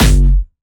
Jumpstyle Kick 3
2 F#1.wav